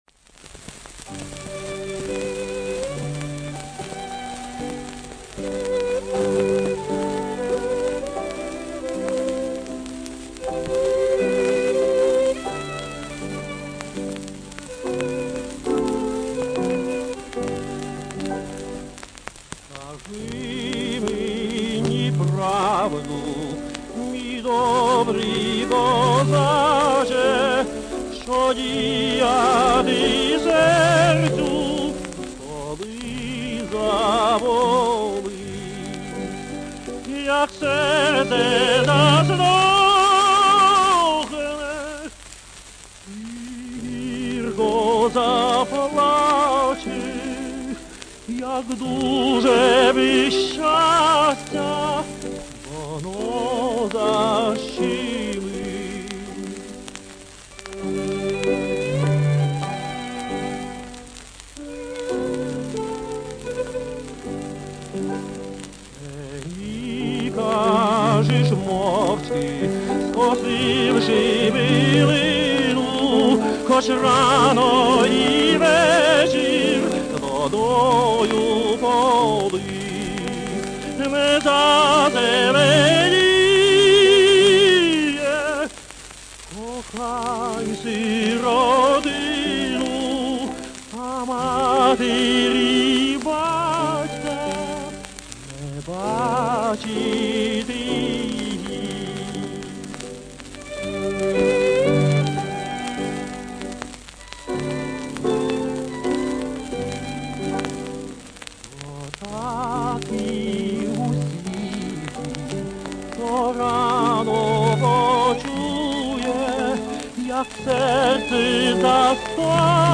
а на другой стороне той же самой пластинки в его исполнении звучит украинский романс «Скажи мені правду».